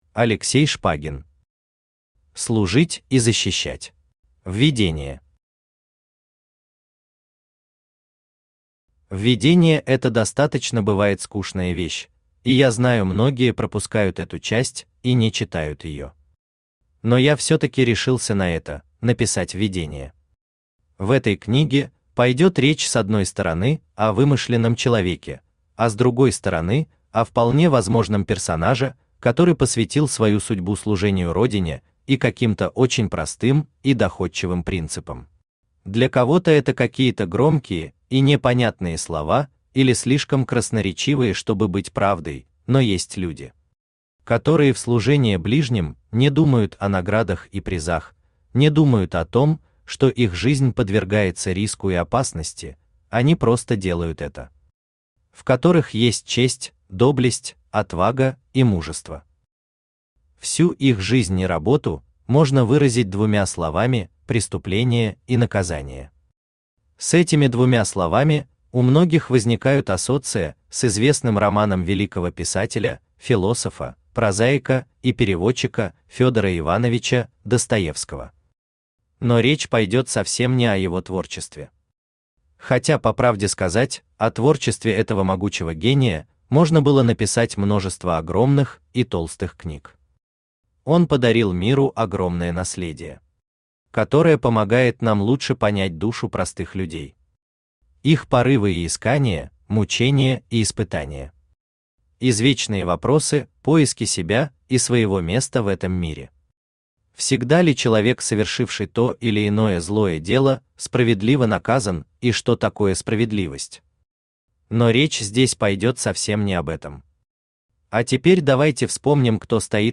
Аудиокнига Служить и защищать | Библиотека аудиокниг
Aудиокнига Служить и защищать Автор Алексей Александрович Шпагин Читает аудиокнигу Авточтец ЛитРес.